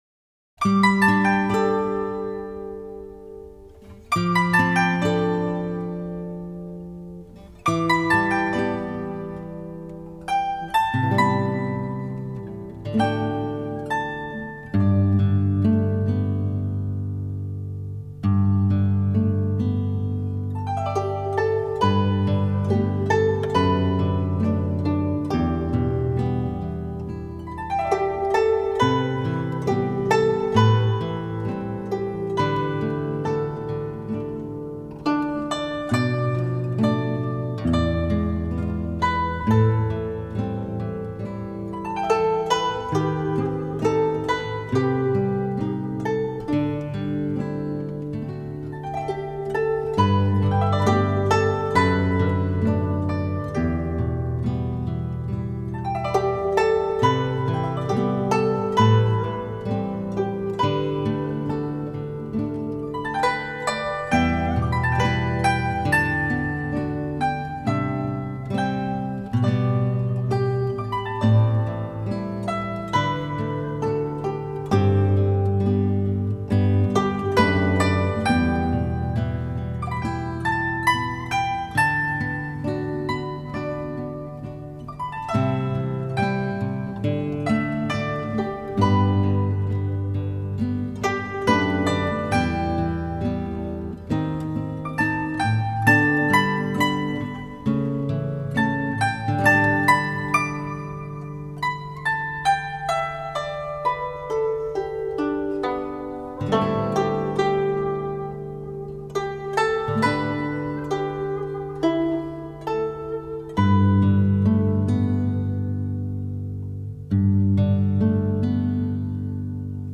古筝演奏